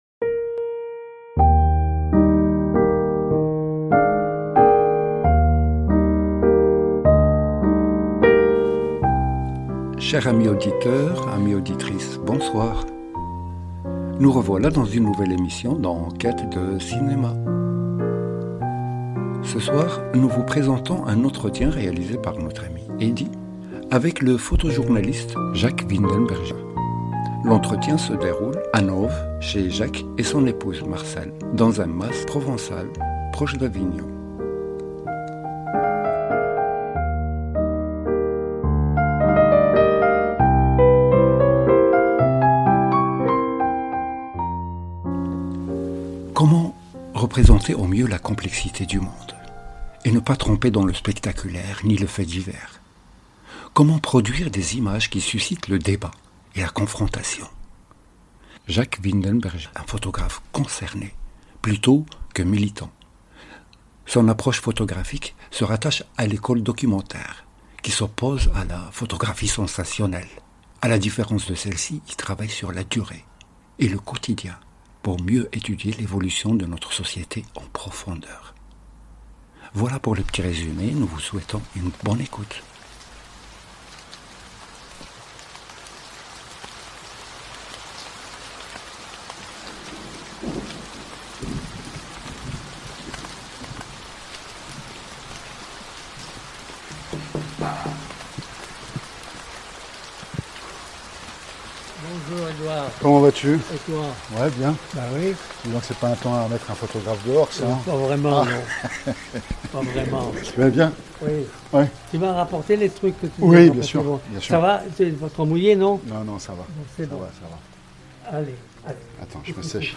Emissions radio, 2011-2026